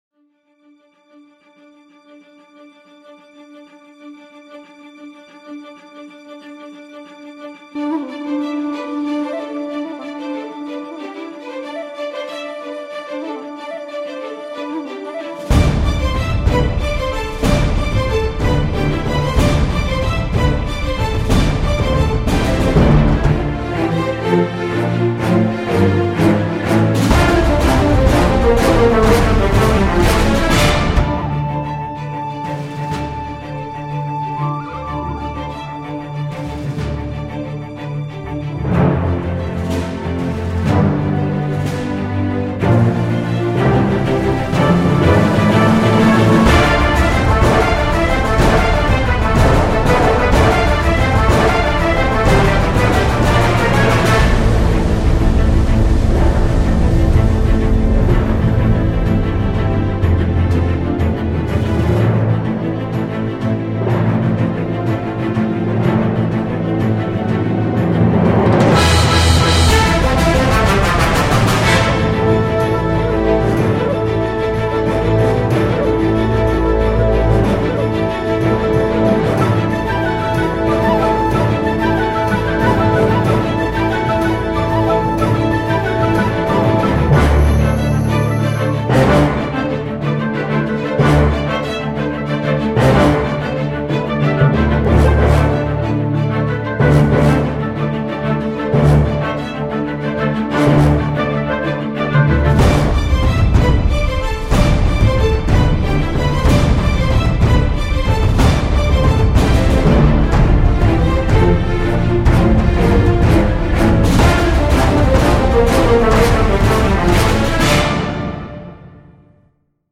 Звуковое оформление для пиратского фильма